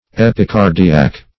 epicardiac - definition of epicardiac - synonyms, pronunciation, spelling from Free Dictionary Search Result for " epicardiac" : The Collaborative International Dictionary of English v.0.48: Epicardiac \Ep`i*car"di*ac\, a. (Anat.) Of or relating to the epicardium.